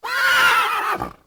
horse.wav